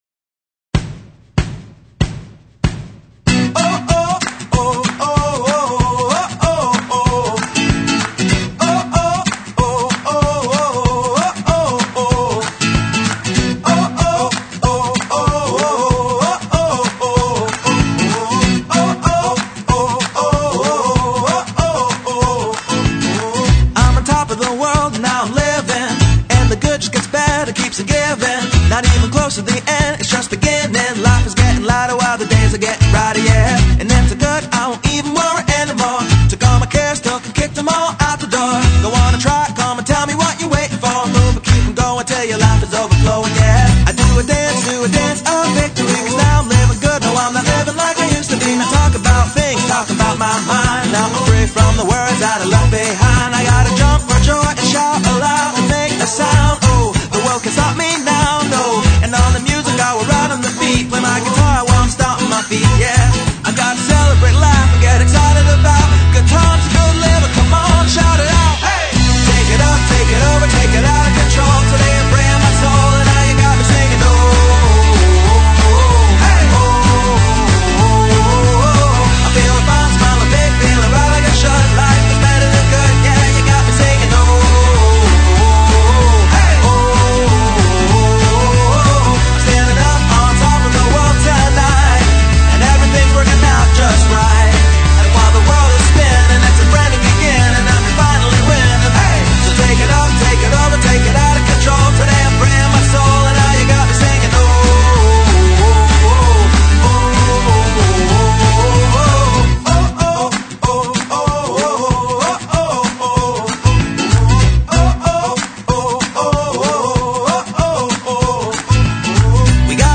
描述：在顶部的世界是一个流行的振奋，宏大和更大的生活轨道充满了积极的歌词和良好的共鸣所有的道路
Sample Rate 采样率16-Bit Stereo 16位立体声, 44.1 kHz